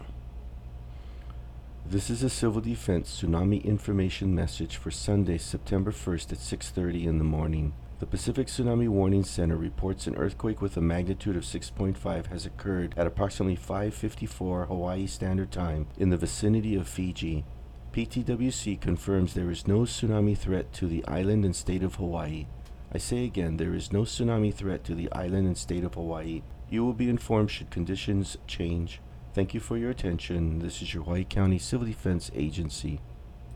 Audio by Hawaii County Civil Defense, issued August Sept. 1, 2019.
A Civil Defense radio message was also distributed.